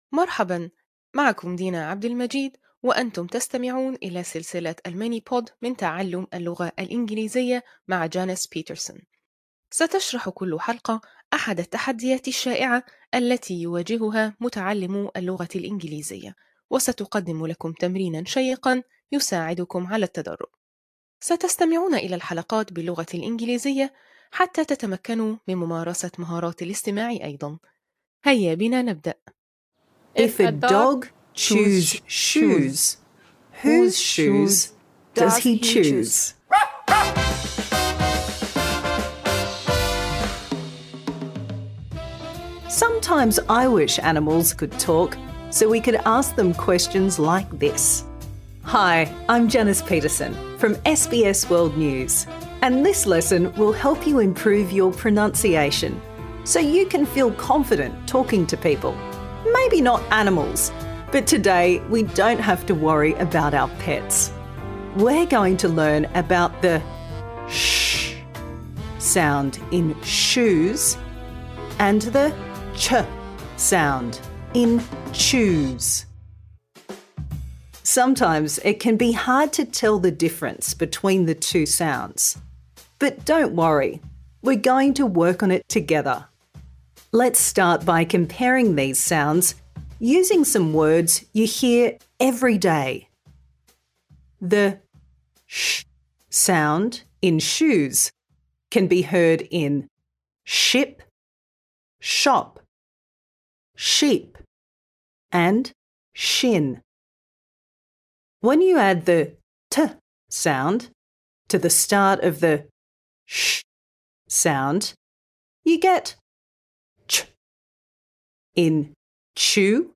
أهداف الدرس: التمكن من نطق الأصوات /tʃ / and /ʃ/ (ch and sh).